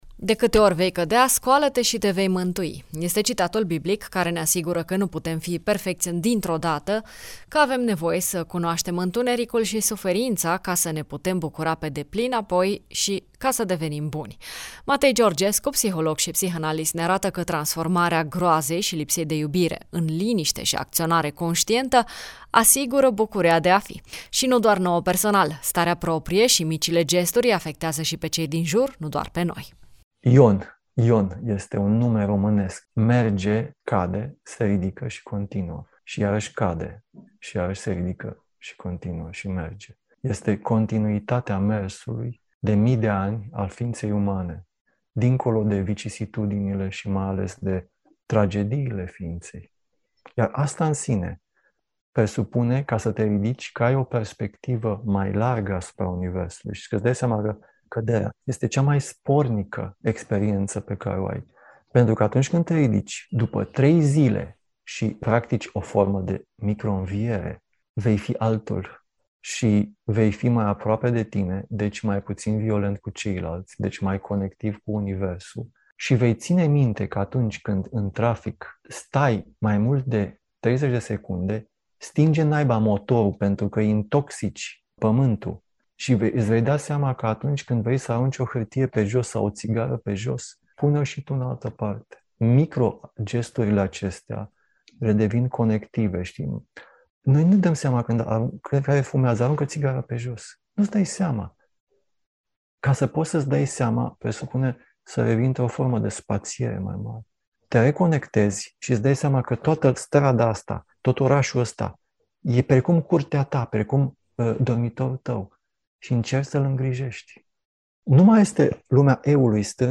psiholog